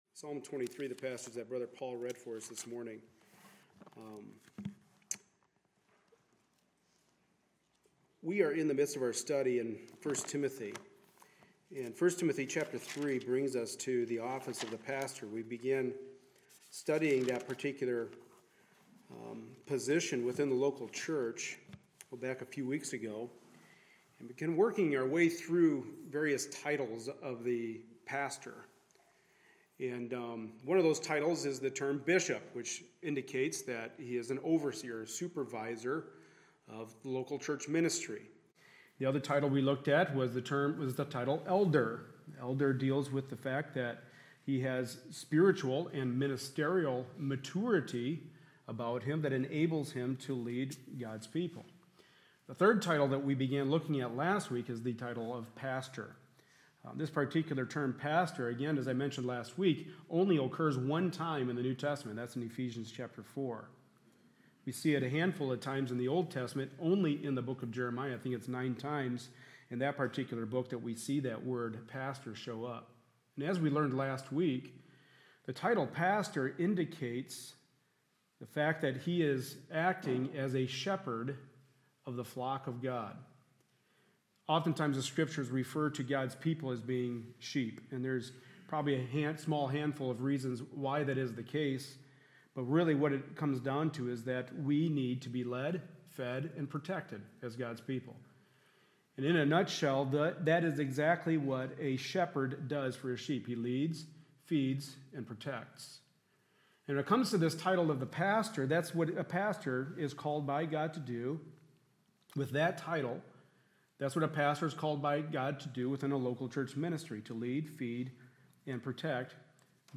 Psalm 23:1-6 Service Type: Sunday Morning Service A look at the perfect Example of pastoral ministry.